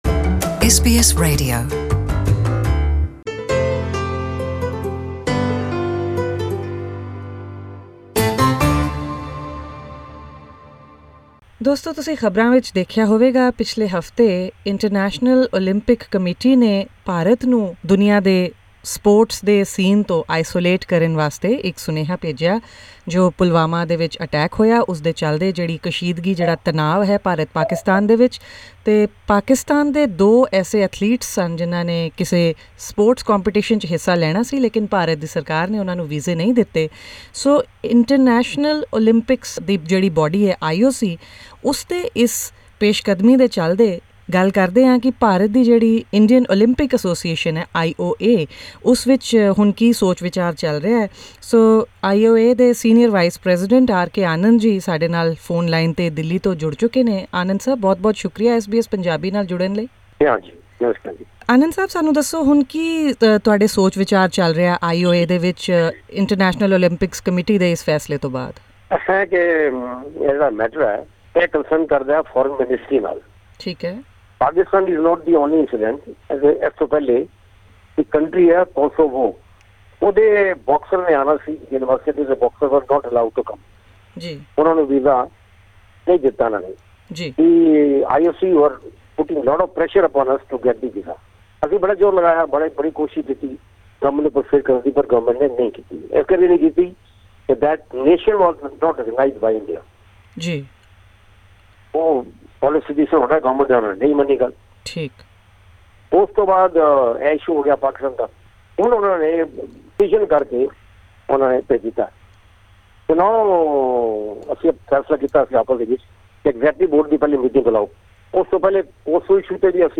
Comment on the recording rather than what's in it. Speaking with SBS Punjabi over the telephone from New Delhi